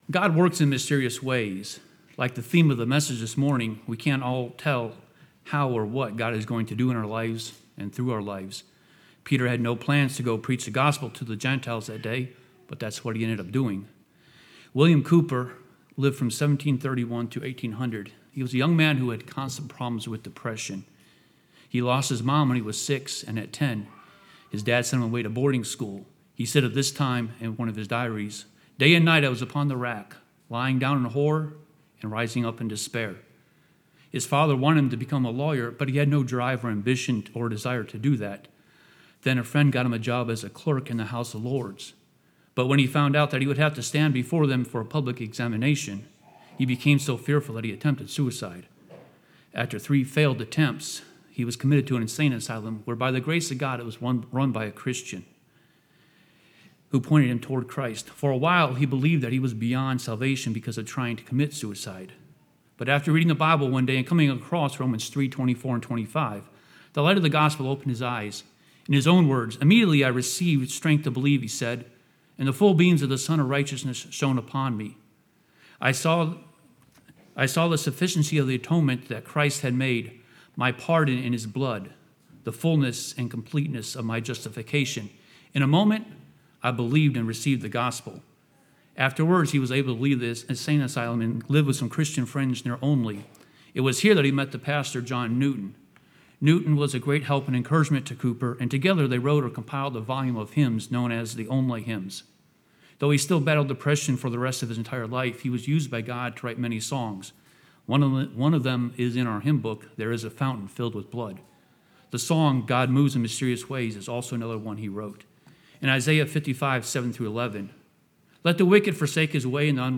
Mission Conference 2025 &middot